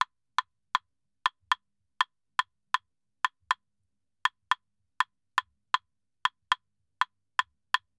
Claves_Salsa 120_2.wav